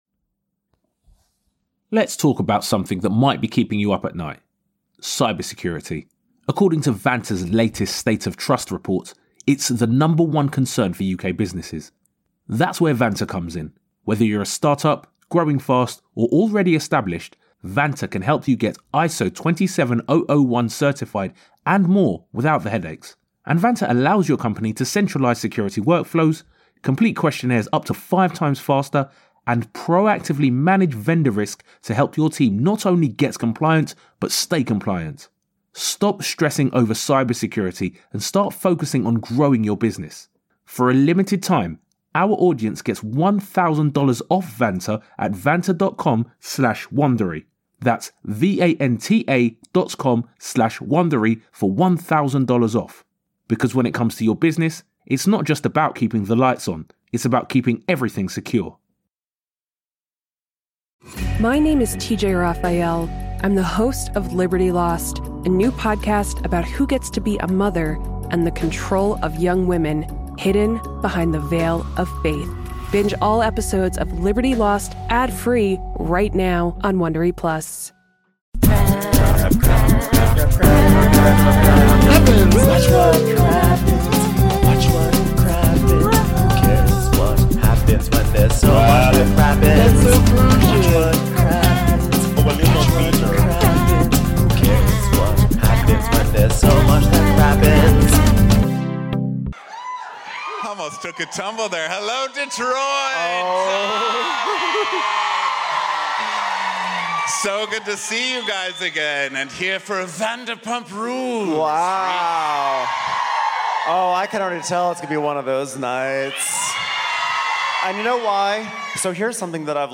We travelled to Detroit to recap a classic Vanderpump Rules episode and had a blast eating all the fried things and laughing with you guys in real life. In this ep, Katie gets revenge on Scheana for spilling Schwartz’ makeout tea by telling everyone that Rob was also kissing someone else.